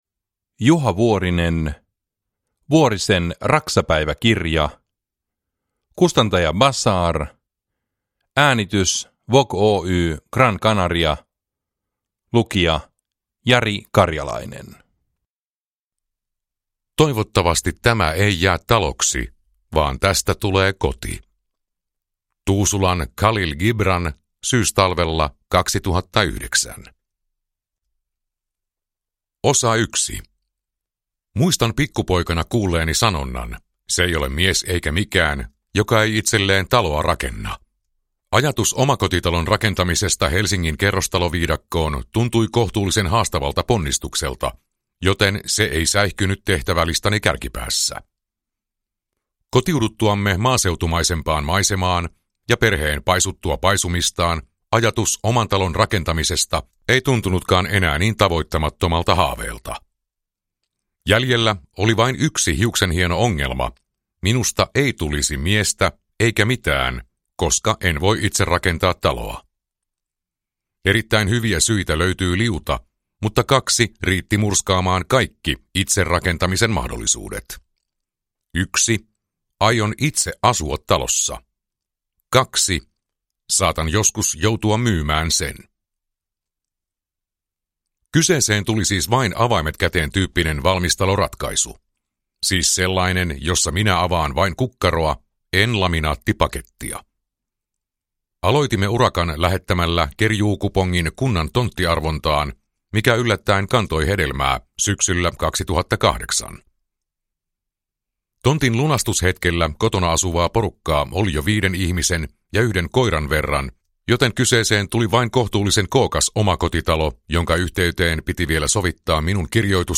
Vuorisen raksapäiväkirja – Ljudbok